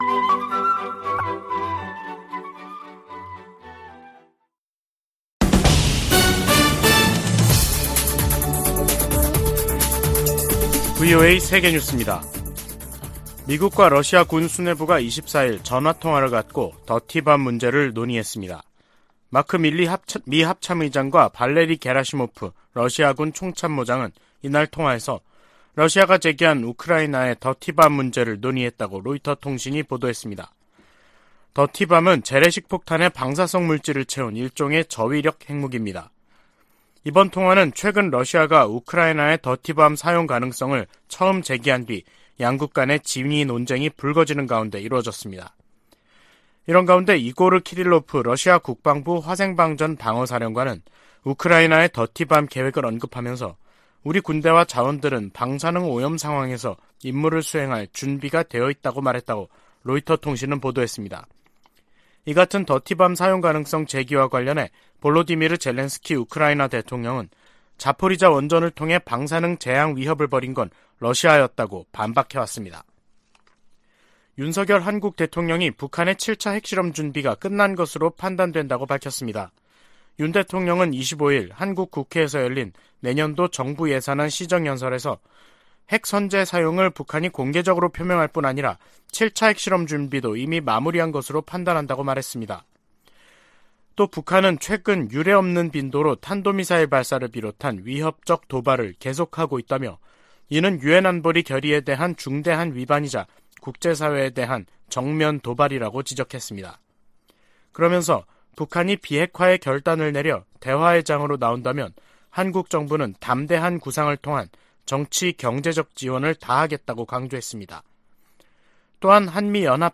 VOA 한국어 간판 뉴스 프로그램 '뉴스 투데이', 2022년 10월 25일 3부 방송입니다. 백악관은 북한이 도발을 계속하고 있는데 우려를 나타내면서, 조건없이 대화할 의향도 재확인했습니다. 국무부는 북한이 7차 핵실험을 준비 중이라는 기존의 평가를 확인하며 동맹·파트너와 만일의 사태에 대비하고 있다고 밝혔습니다. 미국의 전문가들은 시진핑 3기 중국이 북한에 더 밀착하며, 핵실험에 눈 감고 대북제재에 협조 안할 것으로 내다봤습니다.